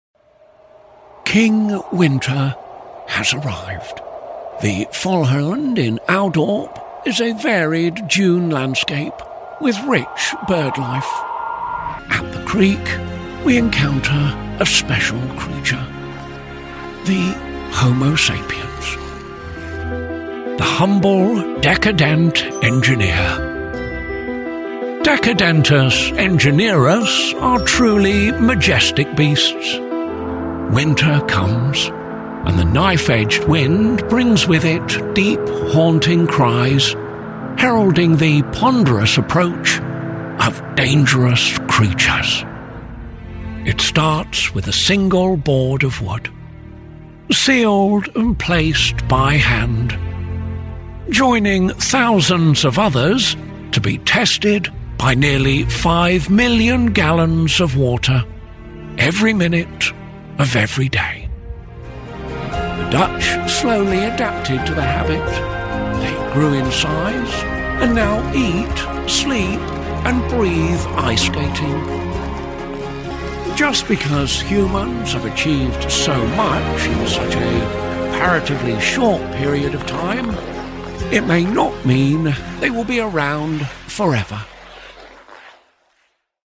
David Attenborough impression voiceover – A flawless, respectful sound-a-like of Sir David for TV, games, and documentaries.
A natural David Attenborough-style narration performed by British male voiceover artist